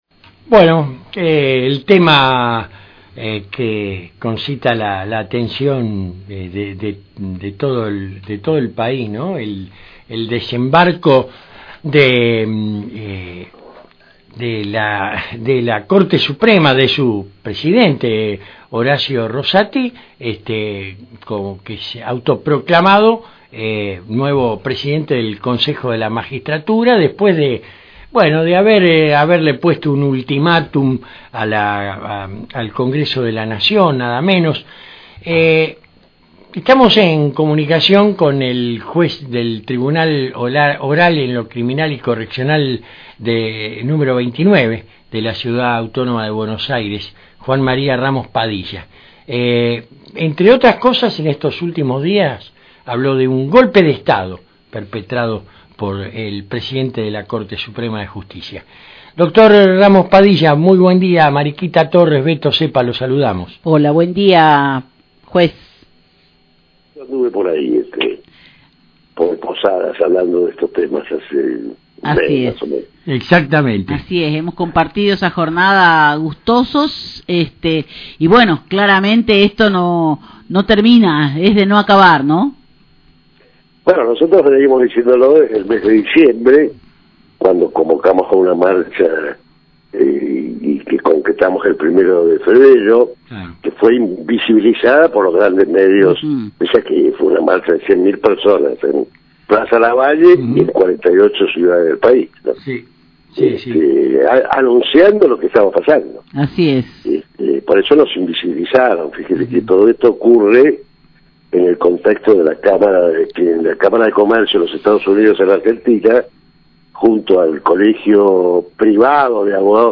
Ramos Padilla fue entrevistado en el programa Contala como quieras de La 99.3 acerca de la crisis de la justicia en la Argentina y la convocatoria a una nueva marcha frente a los tribunales en la Ciudad de Buenos Aires para pedir la renuncia de los integrante de la CSJN que se realizará el 24 de mayo próximo.